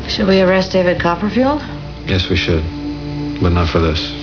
Hear the sarcasm in the X-Files quote at the top of the page.